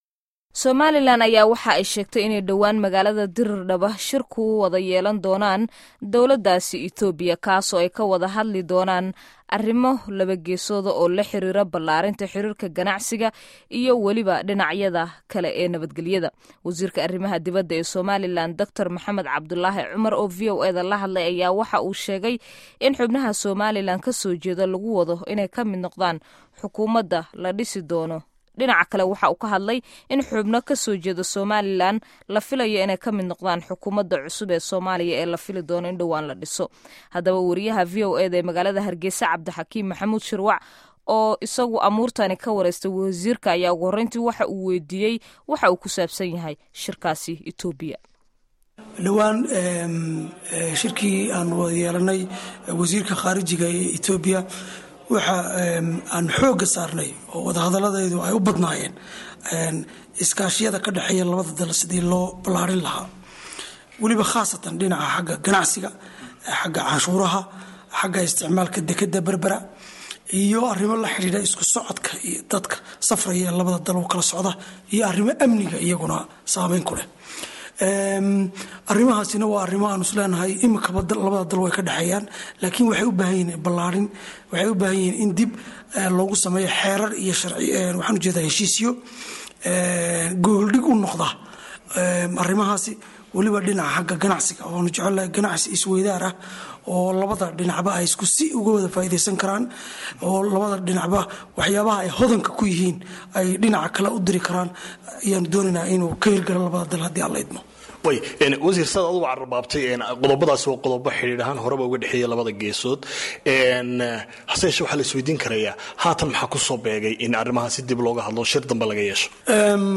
Wareysiyada Somaliland